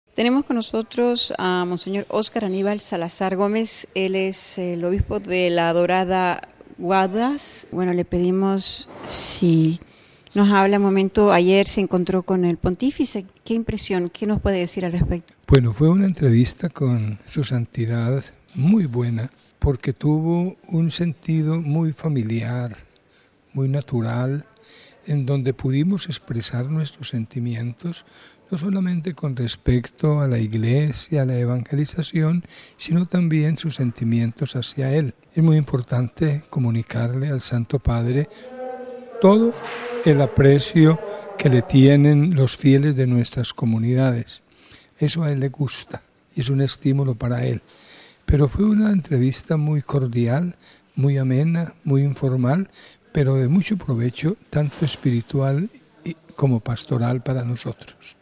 (RV).- (Audio) En nuestras entrevistas a los obispos colombianos, en su visita ad limina, con nosotros el obispo de la Dorada Guaduas, Mons. Oscar Aníbal Salazar, a quien le preguntamos su impresión en su visita al Pontífice, entre otras cosas, el prelado nos dijo que fue un encuentro con un sentido familiar y natural donde se expresaron los sentimientos.